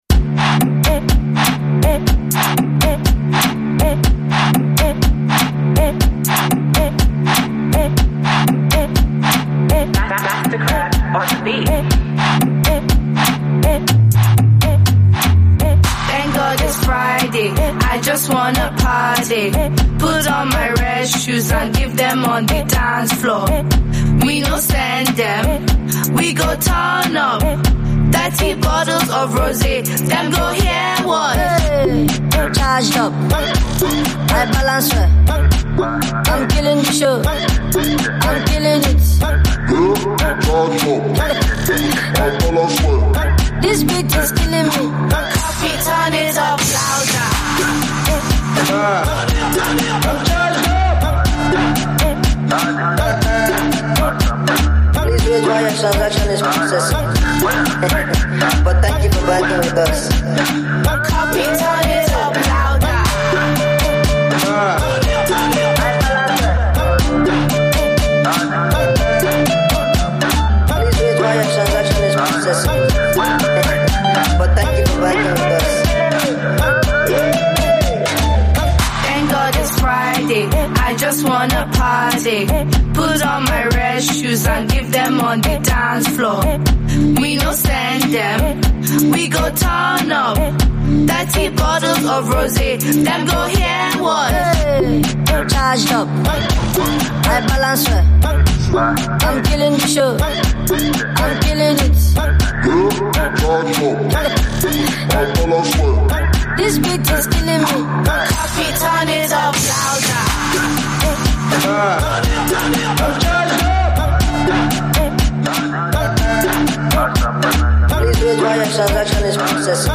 smashing club banger